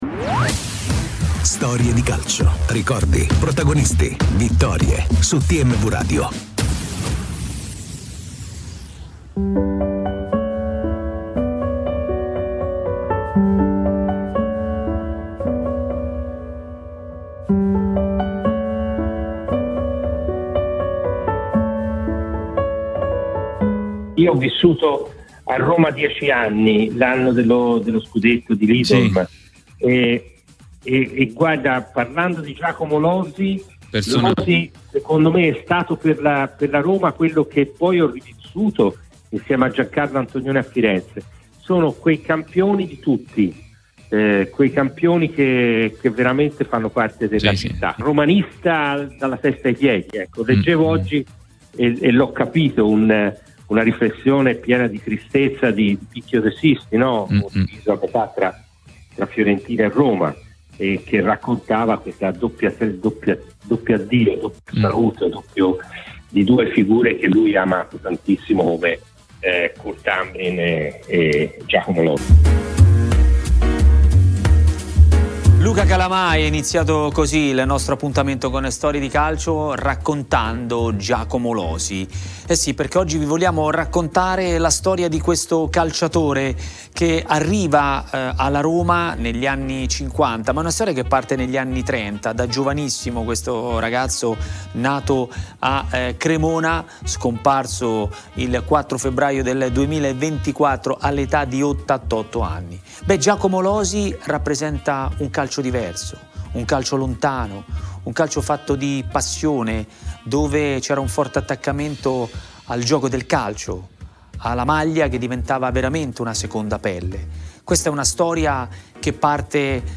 Una storia incredibile che ha raccontato ai microfoni di TMW Radio nel 2017 e che abbiamo voluto ricordare ripercorrendo in uno speciale di Storie di Calcio.